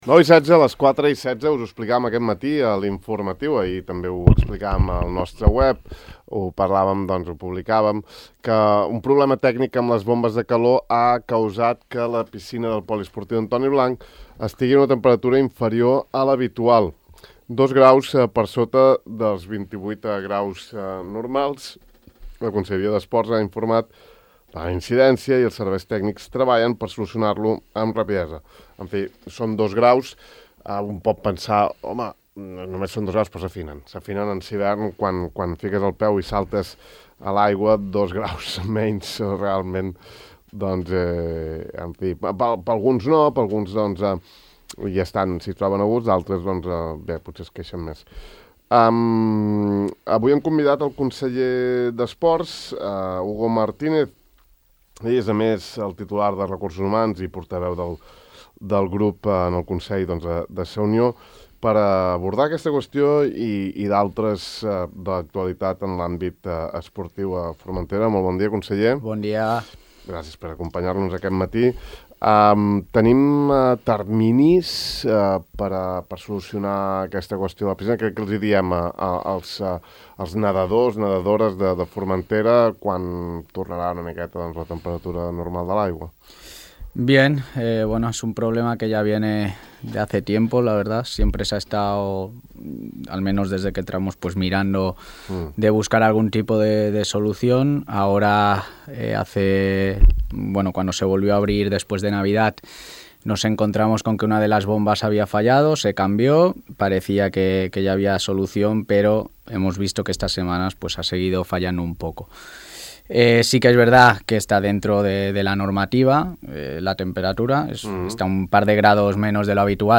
El vicepresident i conseller insular d’Esports, Hugo Martínez, ha anunciat a Ràdio Illa que el Consell ja disposa de “la solució tècnica” per acabar amb els degots que pateix des de fa anys el sostre del Poliesportiu Antoni Blanc, inaugurat el 2012.